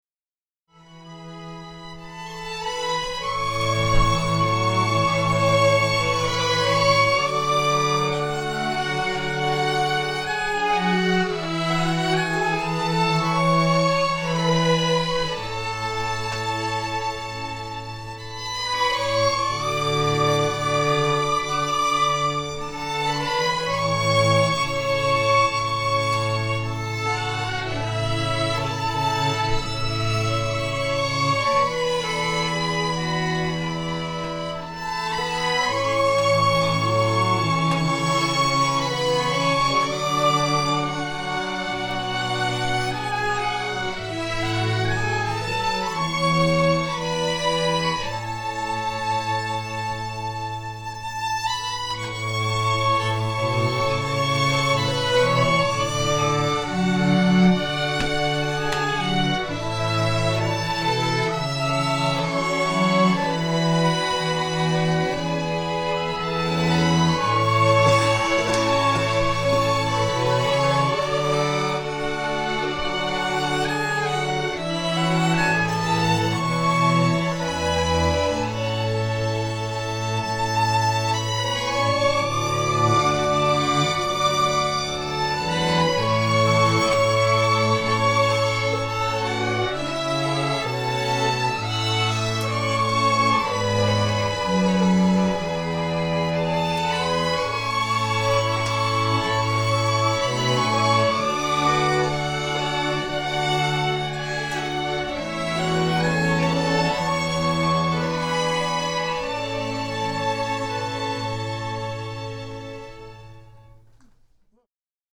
Traditional Scottish Fiddle Music